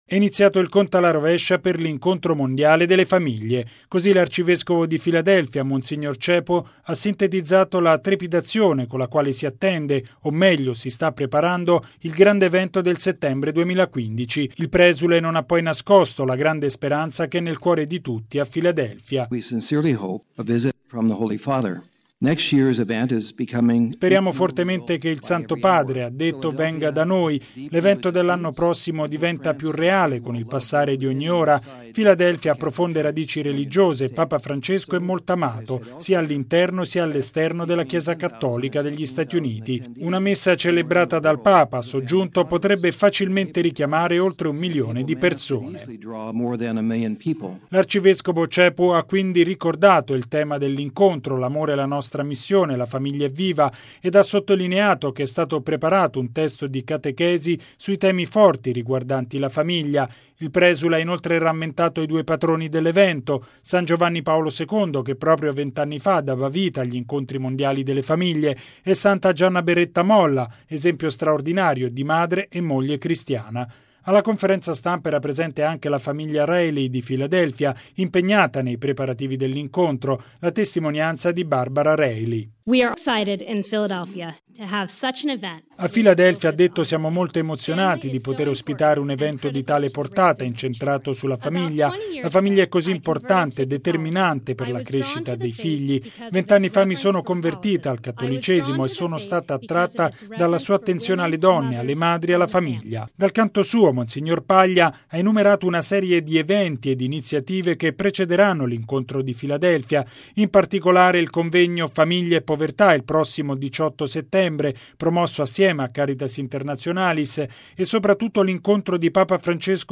Si è tenuta stamani, in Sala Stampa vaticana, la conferenza stampa di presentazione della preparazione all’VIII Incontro mondiale delle Famiglie che si svolgerà a Filadelfia, negli Stati Uniti, dal 22 al 27 settembre dell’anno prossimo.